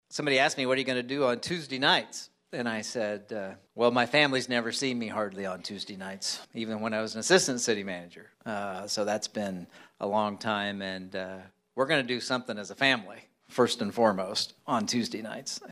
Fehr himself got emotional at Thursday’s reception, saying he will spend more time with his family during retirement.